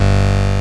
拡張子の前に「_22」とあるものは8ビット・22KHz「_44」とあるものは16ビット・44.1KHzになっています。
今は無き某米国大陸横断クイズ番組の音をヒントにしました。QuizSound 1は解答ボタンを押したときの音、2は正解時の音、3は誤答時の音です。